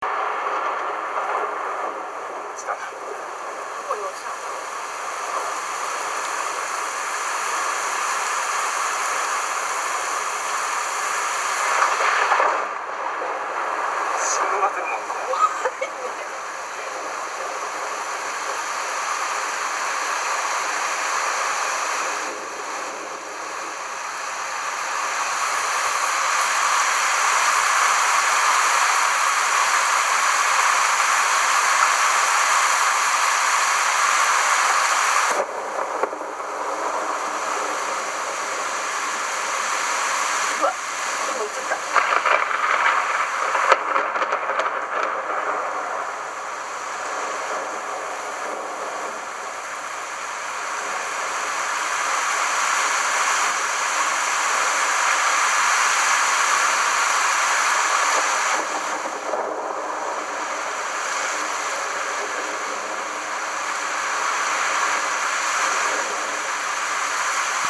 thunder.mp3